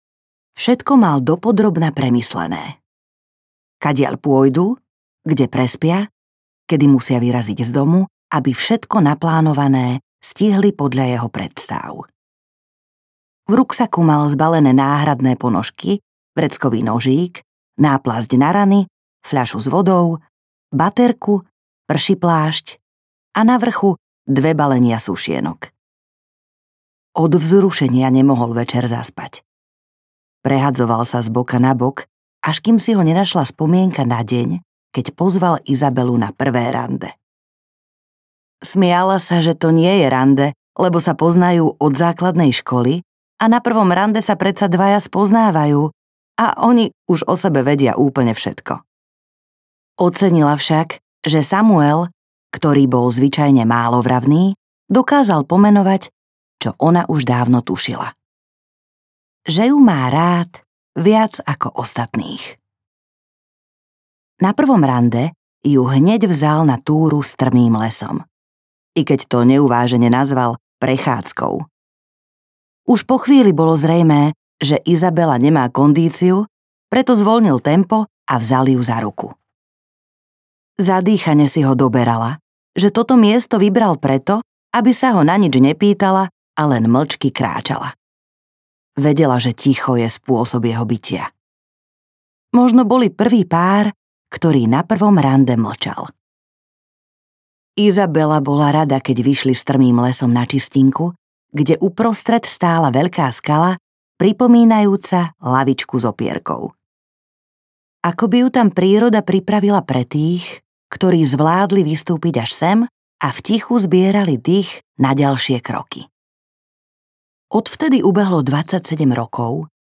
Tak leť audiokniha
Ukázka z knihy
• InterpretLujza Garajová Schrameková